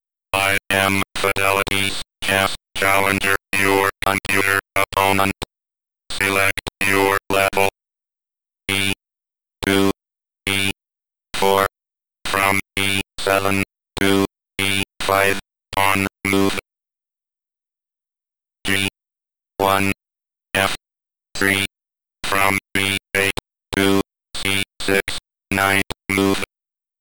피델리티 보이스 체스 챌린저(Fidelity Voice Chess Challenger, 1979), 최초의 음성 지원 체스 컴퓨터
보이스 체스 챌린저의 음성 출력 샘플